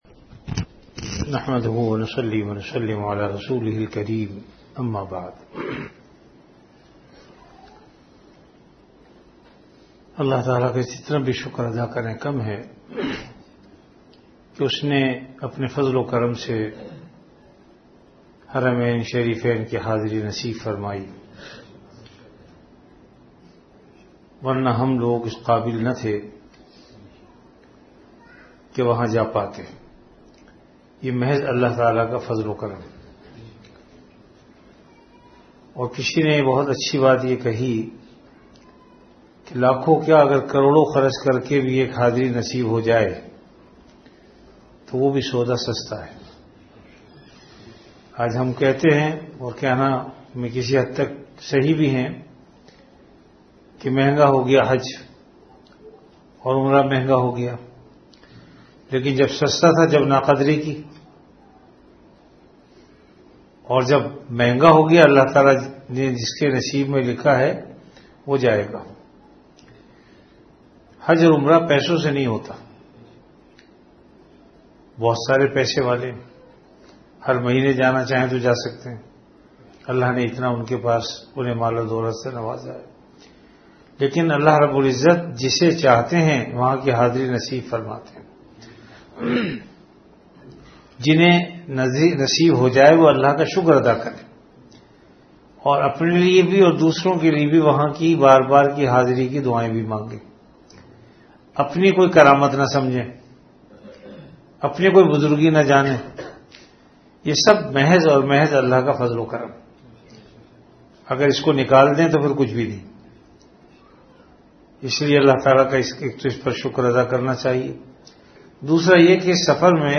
Delivered at Home.
Category Bayanat